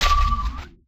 UIClick_Mallet Low Pitch Heavy 02.wav